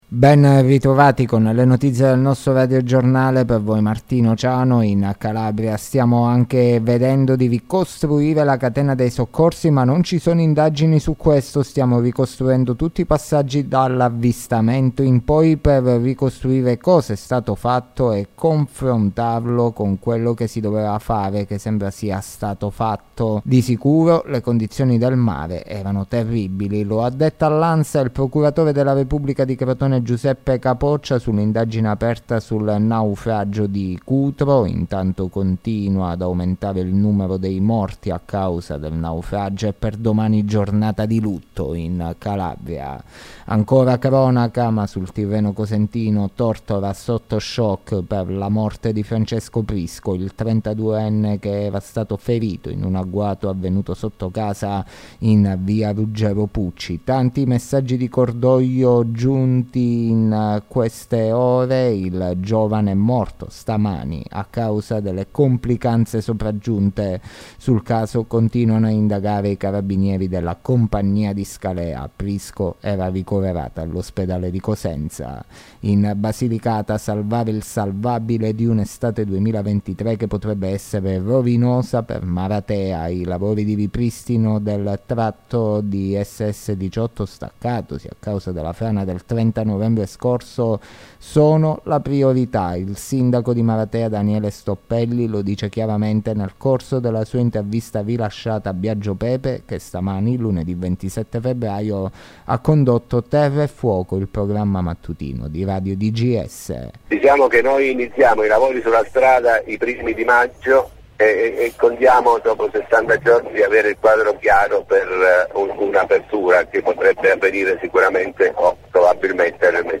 LE NOTIZIE DELLA SERA DI LUNEDì 27 FEBBRAIO 2023